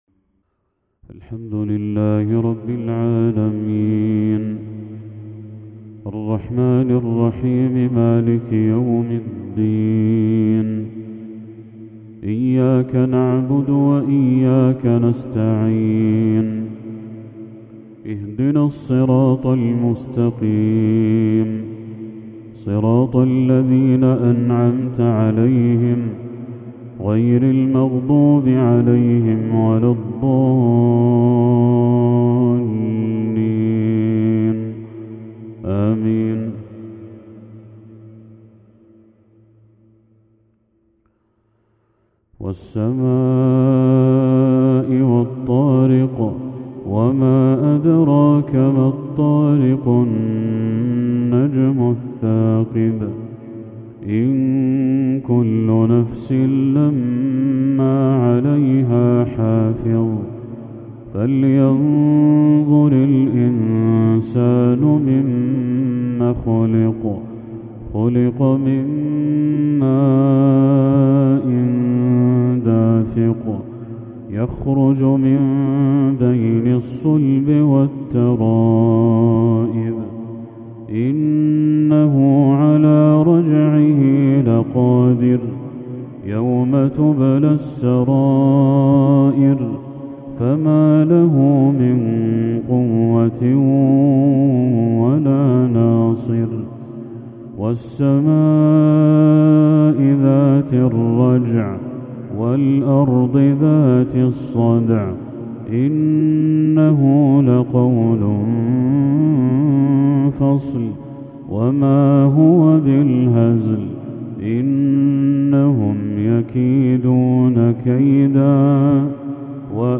تلاوة لسورتي الطارق والمسد